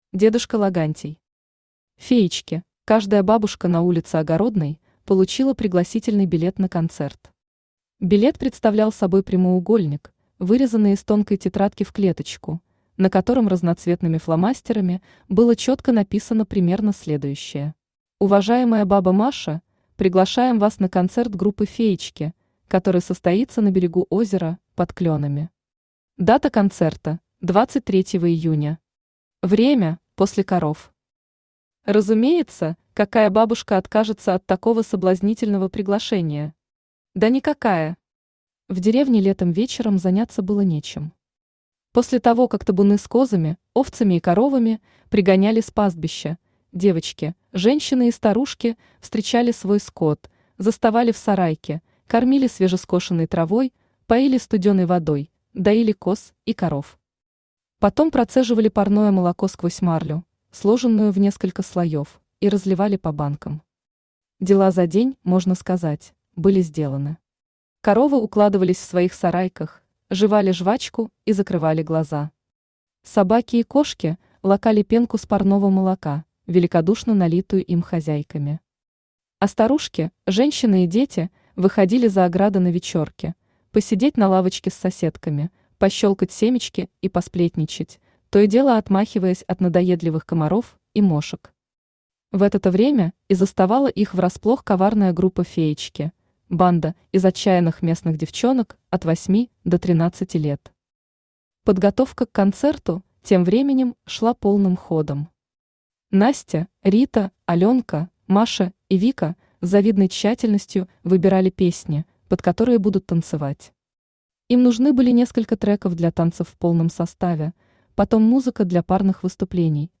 Аудиокнига Феечки | Библиотека аудиокниг
Aудиокнига Феечки Автор дедушка Логантий Читает аудиокнигу Авточтец ЛитРес.